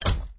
snd_ui_sold.wav